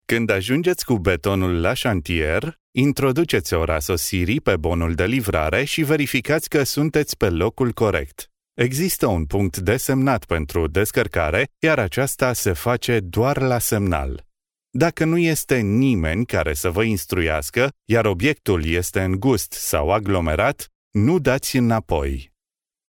Vidéos explicatives
I am a male professional Romanian native voice talent since 1999.
Neumann U87 and TLM 103 microphones
BarytonBasseProfond
ChaleureuxDe la conversationAmicalFiableExpérimentéPolyvalentEngageantDynamiqueAssuréContentSur de soiReliableBien informéArticulerCroyableAmusementInstruitSarcastiqueCalmeIntelligentInformatifNaturel